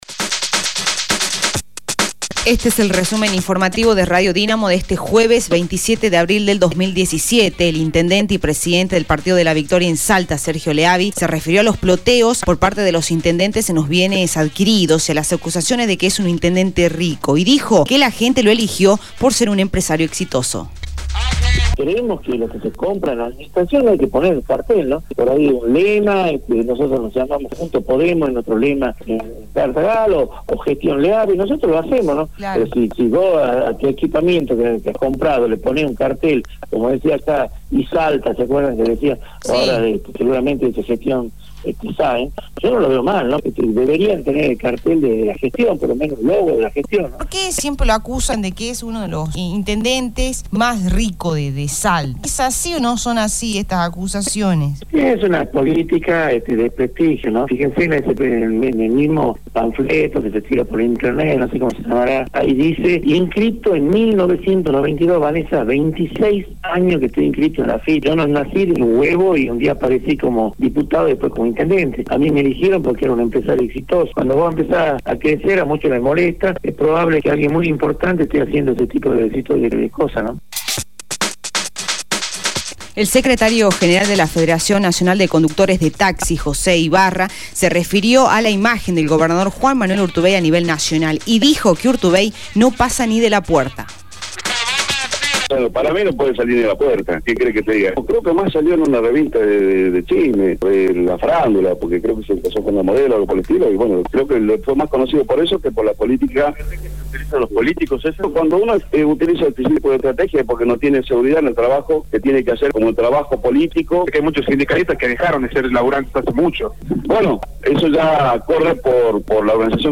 Resumen Informativo de Radio Dinamo del día 27/04/2017 1° Edición